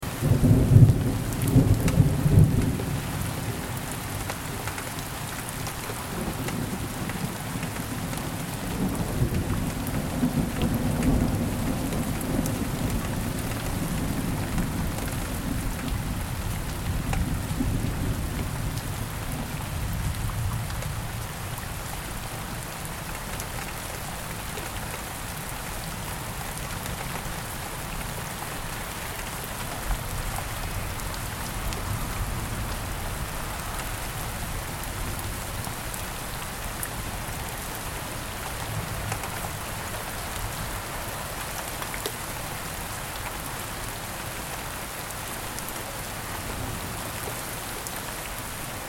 دانلود صدای طوفان 31 از ساعد نیوز با لینک مستقیم و کیفیت بالا
جلوه های صوتی
برچسب: دانلود آهنگ های افکت صوتی طبیعت و محیط دانلود آلبوم صدای طوفان از افکت صوتی طبیعت و محیط